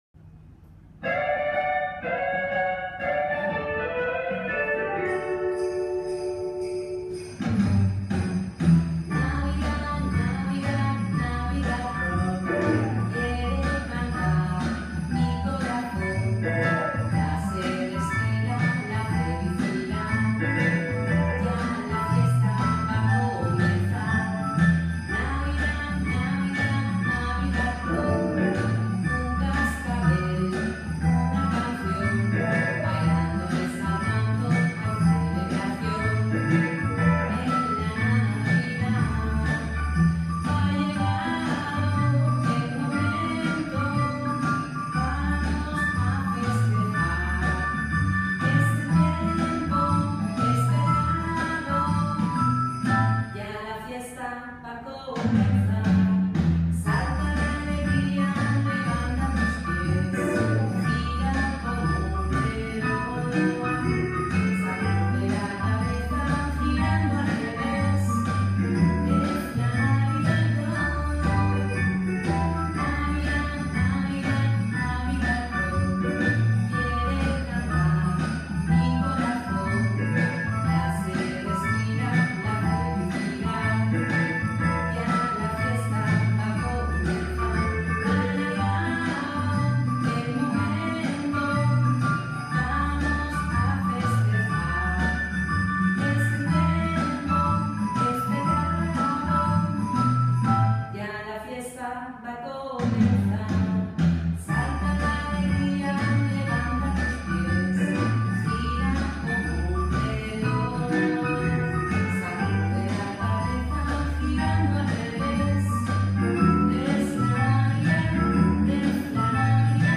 con Voz